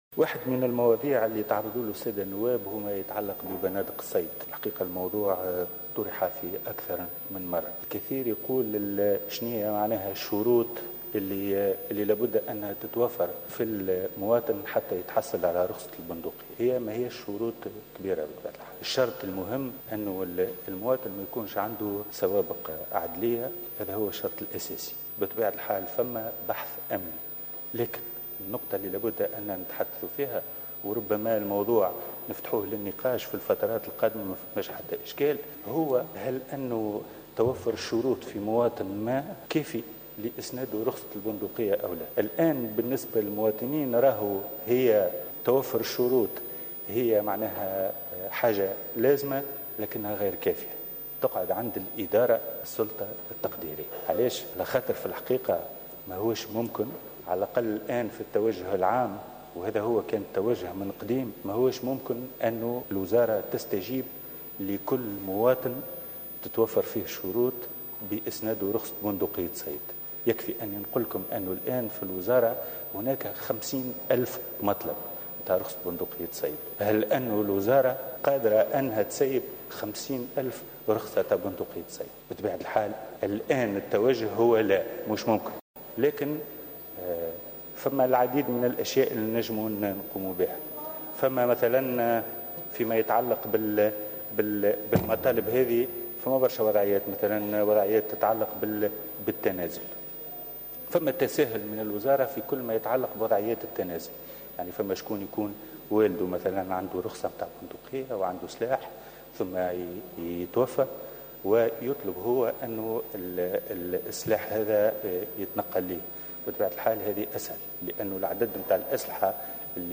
قال وزير الداخلية هادي مجدوب، لدى حضوره اليوم الثلاثاء للجلسة العامة بمجلس نواب الشعب المخصصة لمناقشة ميزانية الدولة لسنة 2017 والمصادقة على ميزانية وزارة الداخلية، إن 50 ألف مطلب للحصول على بنادق صيد تقدم بها مواطنون للوزارة.